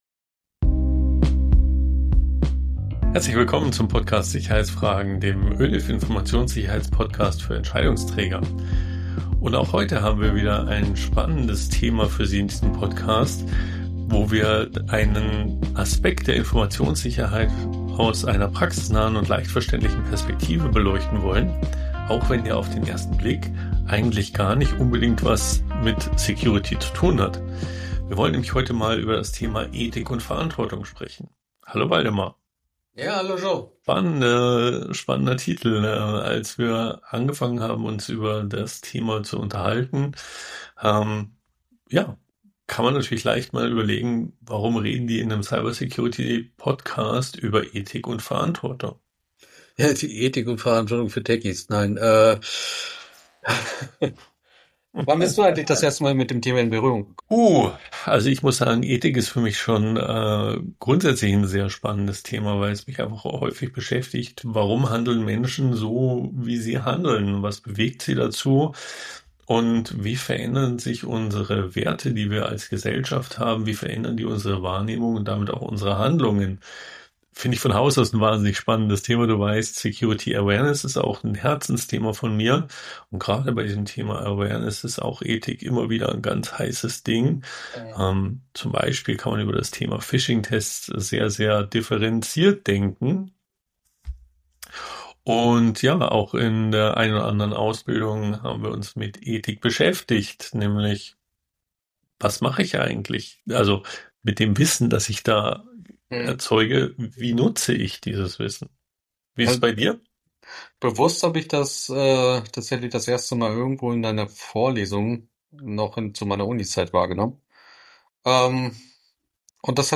Die Hosts diskutieren, warum Ethik nicht nur ein philosophisches Konzept, sondern ein praktischer Leitfaden für den sicheren Umgang mit Technologie ist.